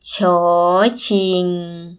∨ shoor _ shing
shoor-shing.mp3